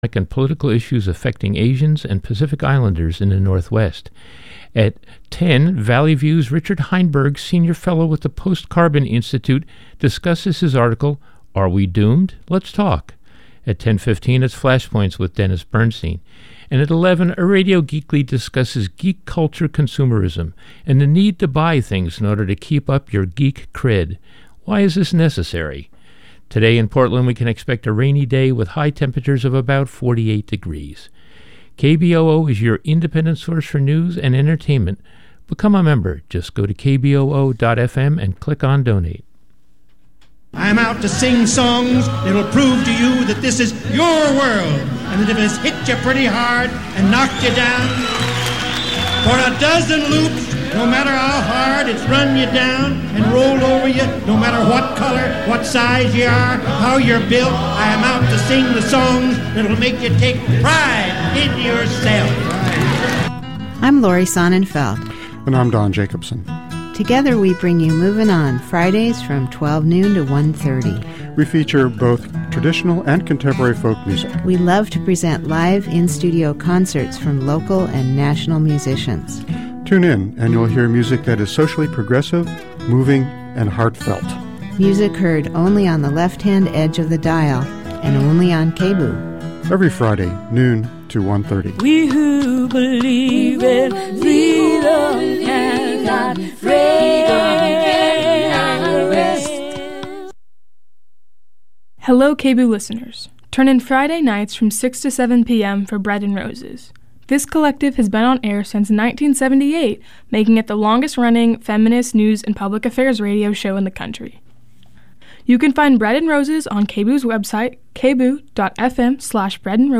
This is a re-broadcast of Positvely Revolting from July 7th, 2017